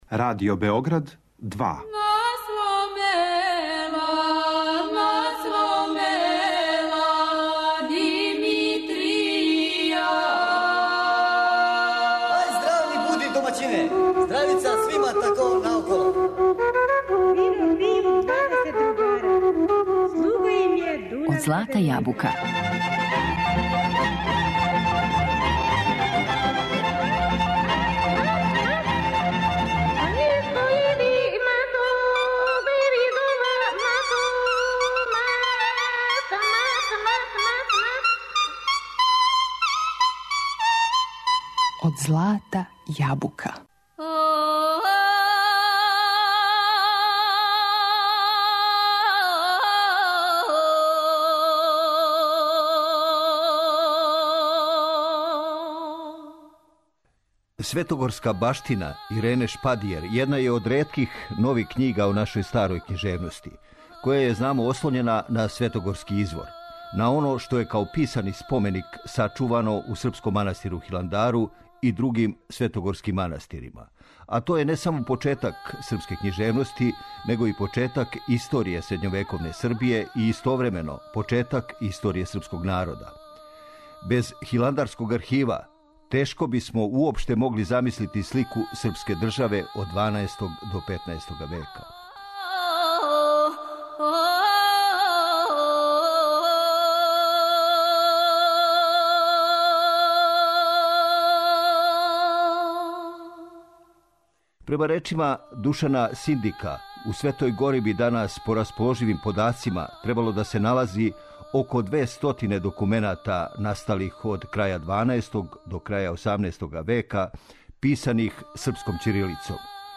Без хиландарског архива тешко бисмо могли замислити слику српске државе од 12. до 15. века. Текст приредио и читао новинар